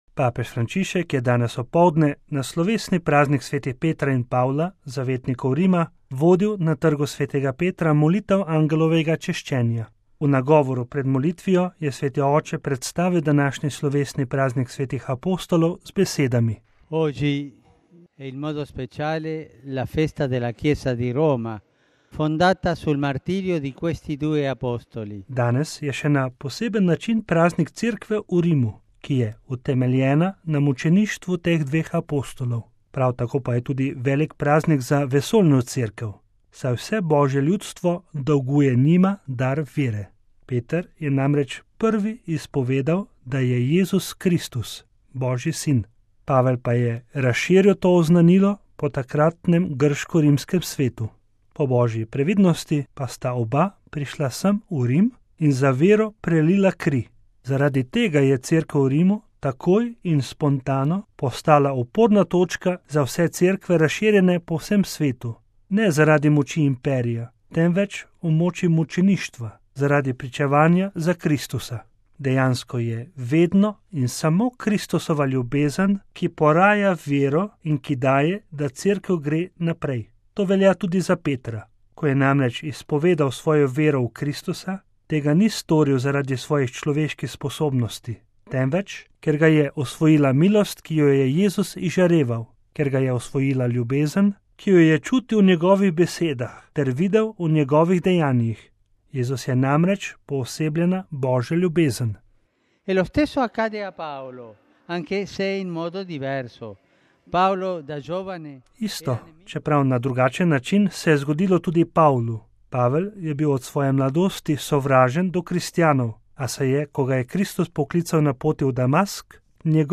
TRG SV. PETRA (sobota, 29. junij 2013, RV) – Papež Frančišek je danes ob poldne na slovesni praznik svetih Petra in Pavla, zavetnikov Rima, vodil na trgu sv. Petra molitev Angelovega češčenja.